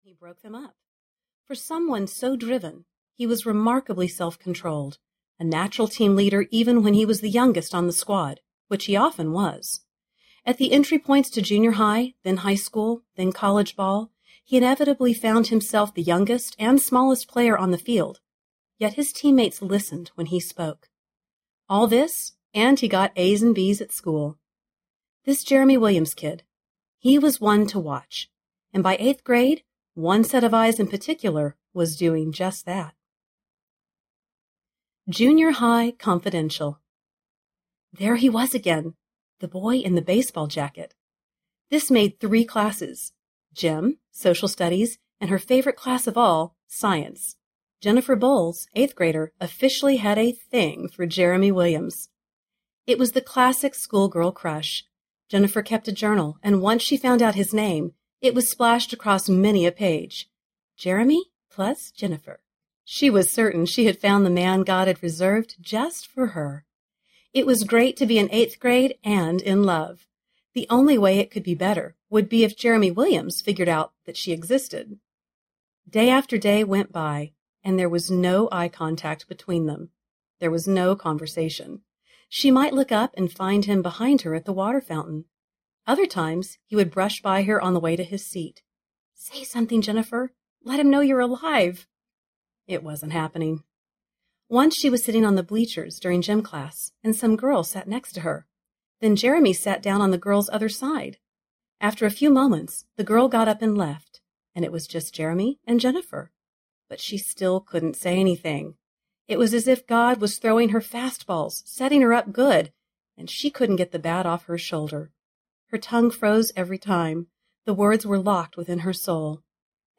Tenacious Audiobook
Narrator
6.65 Hrs. – Unabridged